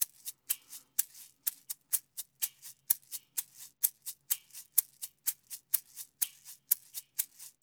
LAY SHAKER.wav